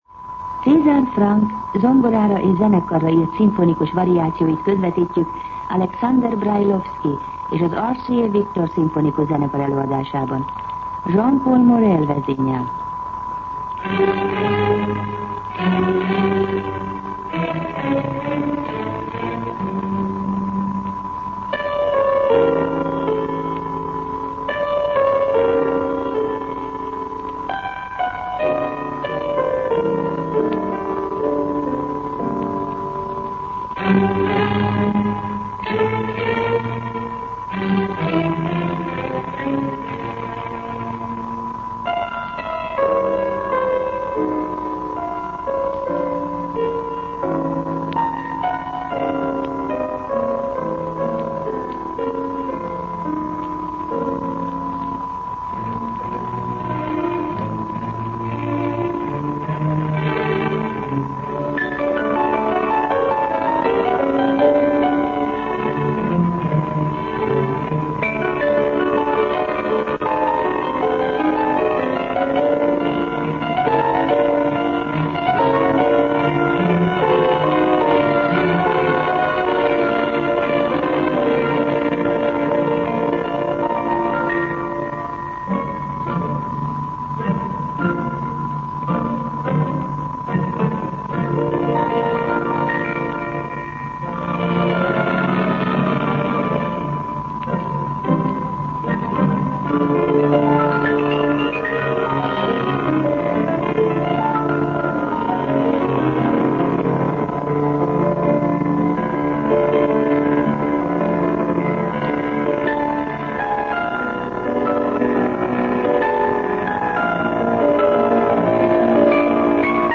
Zene
zongorára és zenekarra